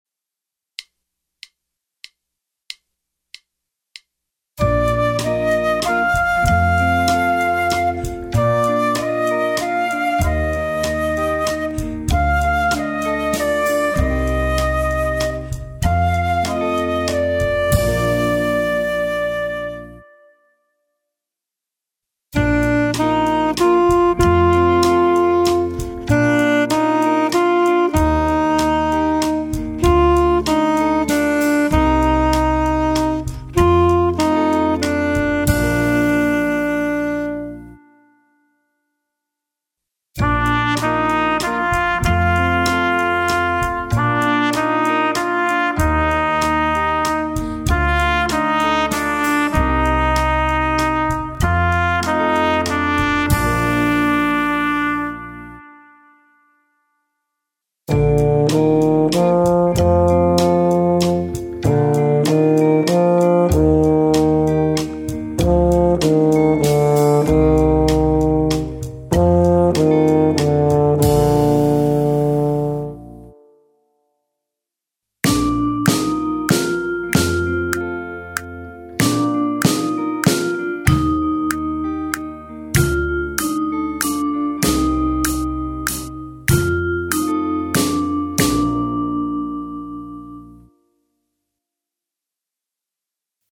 Gattung: Komplette Schule für Bläserklasse & Instrumental
Besetzung: Blasorchester